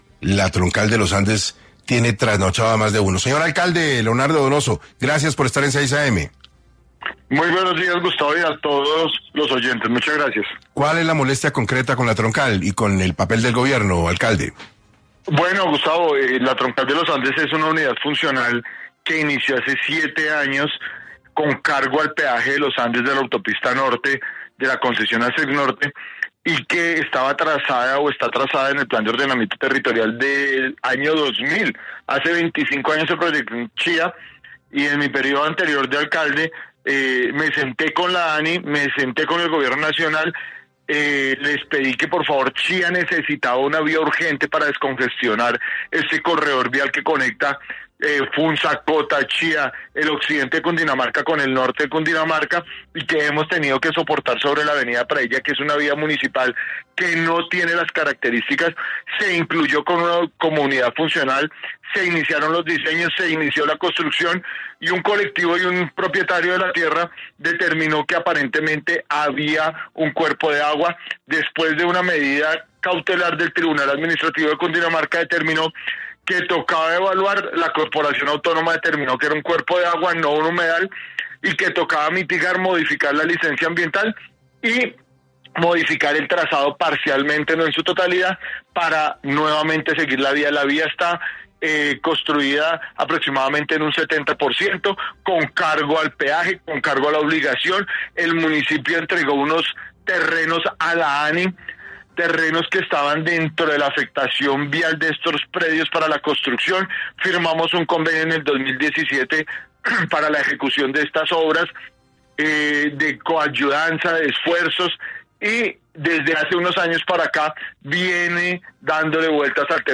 El mandatario local expresó su frustración en 6AM de Caracol Radio por la suspensión indefinida de la audiencia pública para la modificación de la licencia ambiental de la Troncal de los Andes.